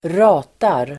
Ladda ner uttalet
Uttal: [²r'a:tar]